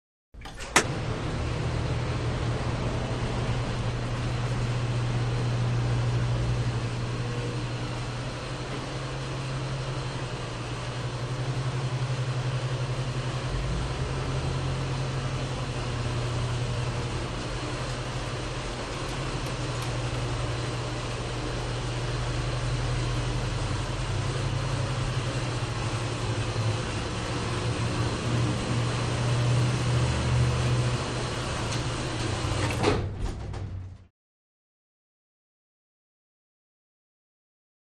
Elevator - Modern, More Low End, Up & Down Movement